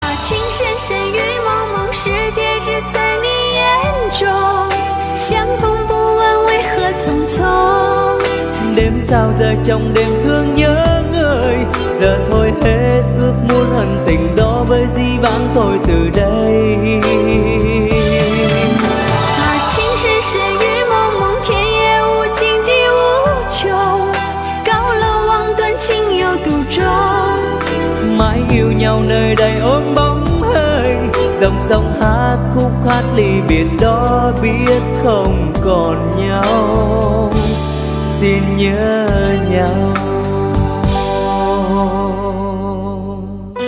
Nhạc Chuông Nhạc Hoa